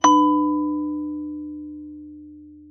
ding_notice.ogg